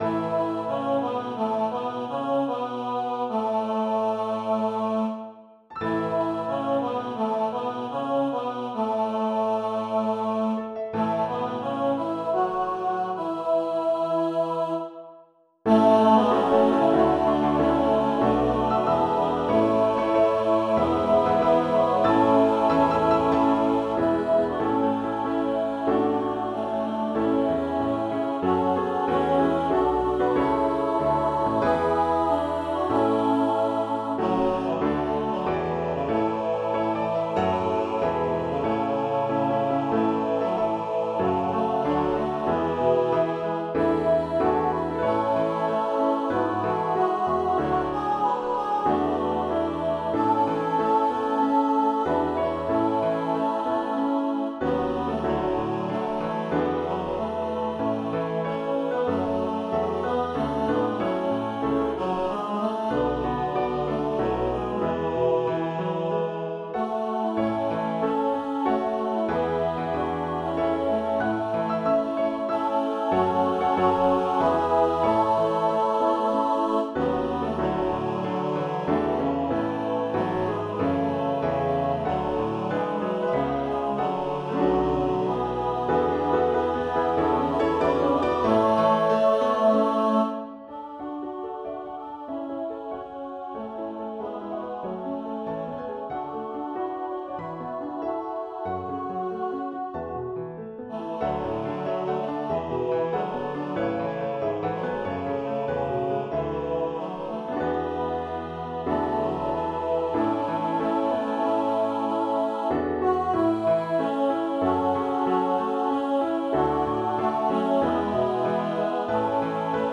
คำสำคัญ : บรรเลง, ดนตรี